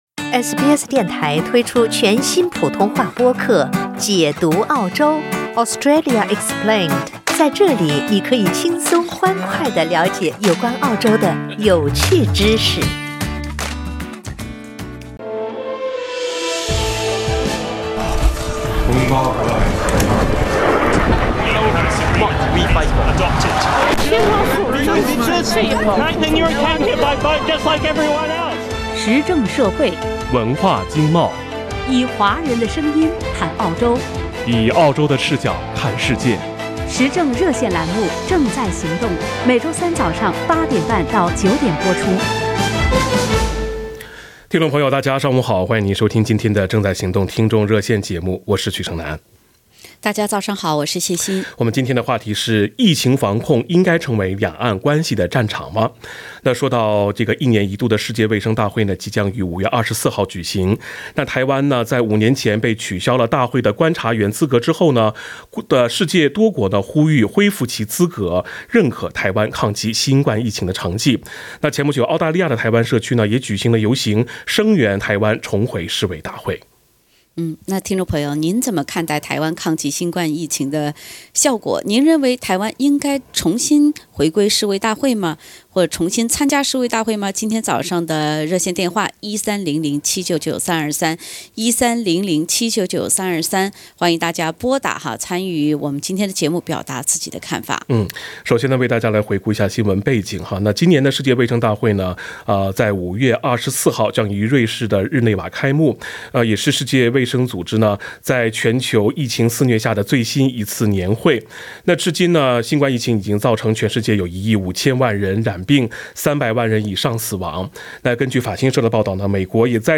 您认为台湾应该重新参加世卫大会吗？（点击封面图片，收听完整热线节目）